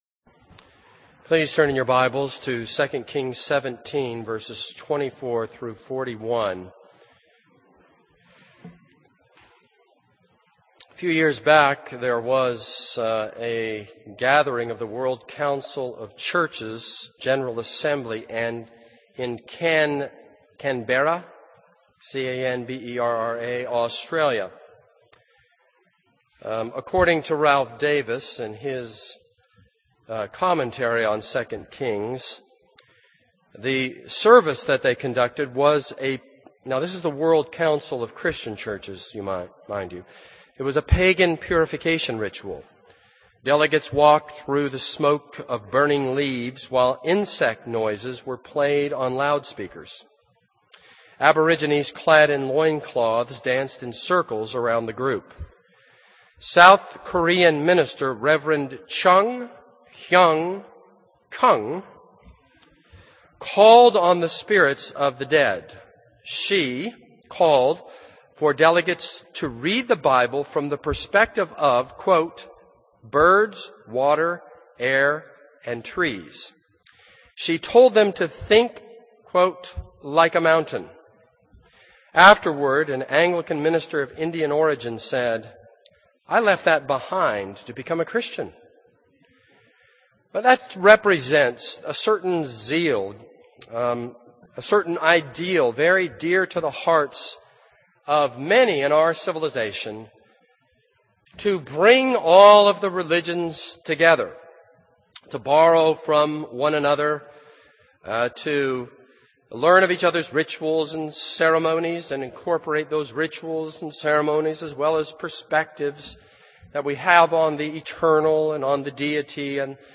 This is a sermon on 2 Kings 17:24-41.